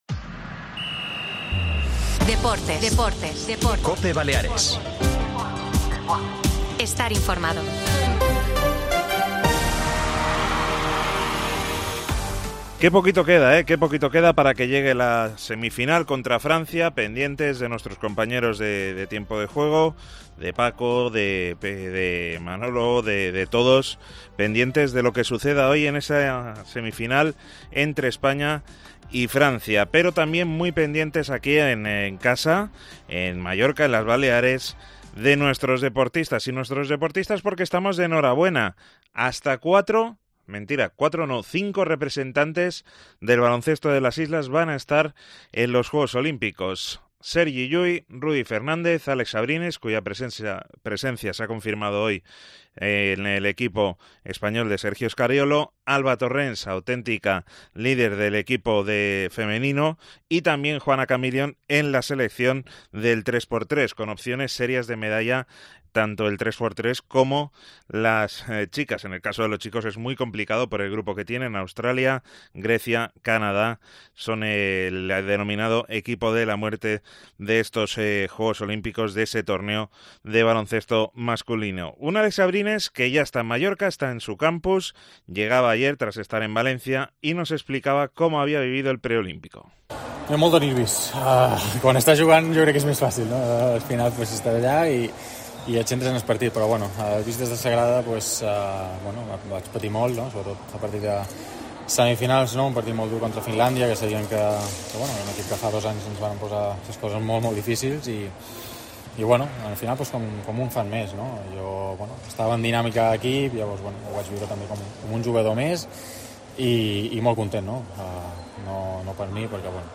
Escuchamos a Álex Abrines, cuya presencia en los juegos se ha confirmado hoy mismo, en la presentación oficial de su campus que se lleva a cabo en Muro